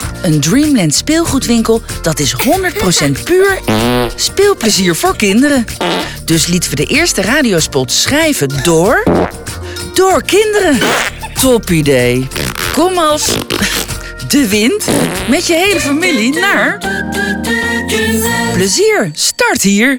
DREAMLAND_NED20_RAD_Scheetjes_Mix1.wav